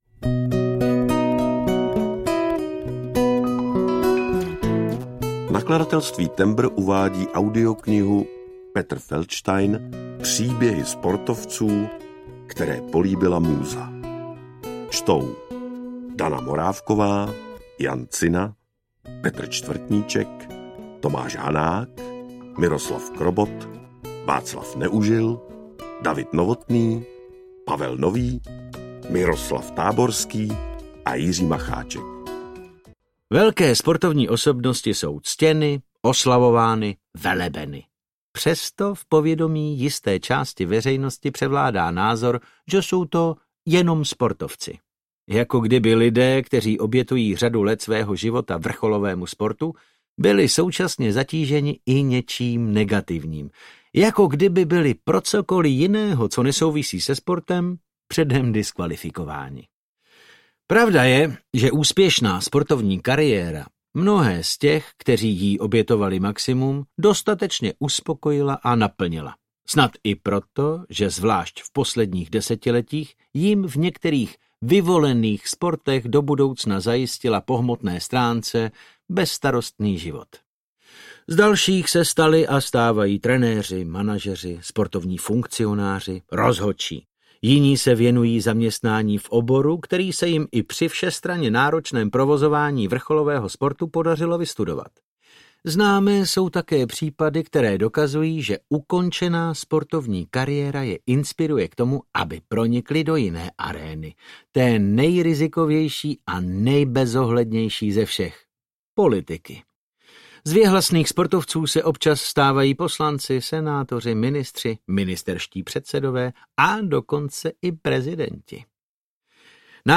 Příběhy sportovců, které políbila múza audiokniha
Ukázka z knihy